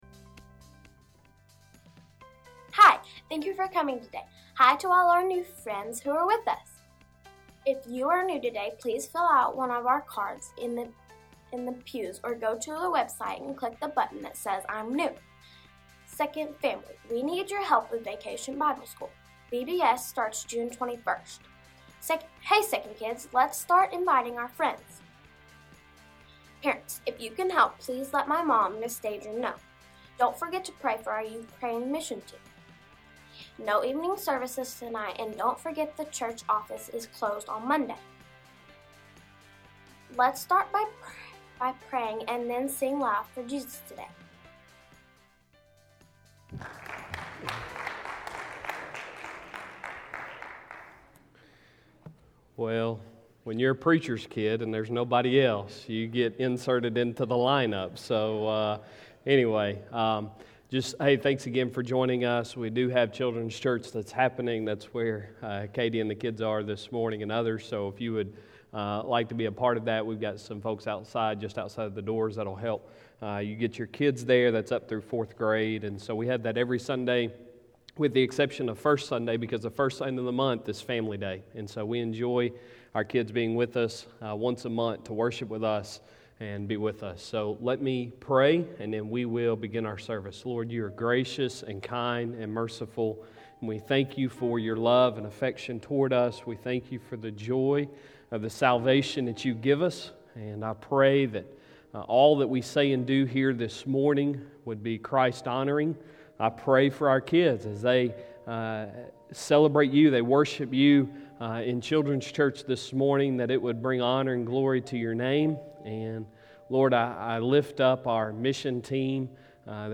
Sunday Sermon May 30, 2021